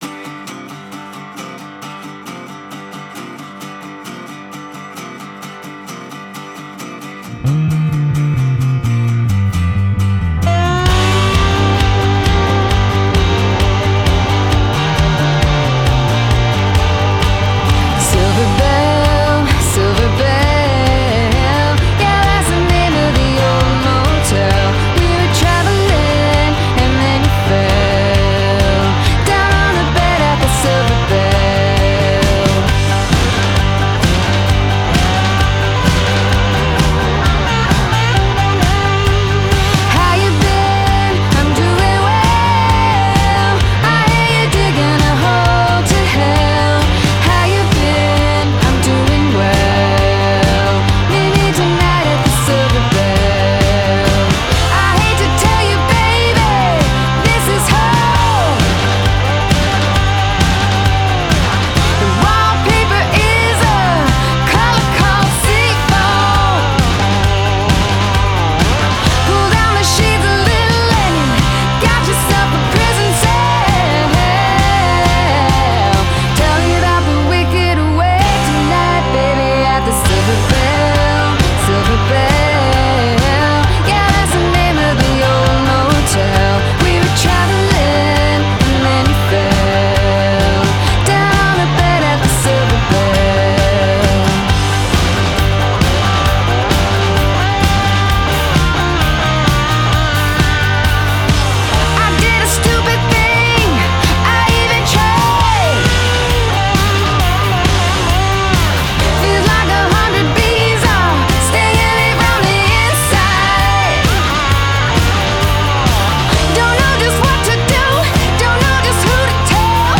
I love her voice, so powerful.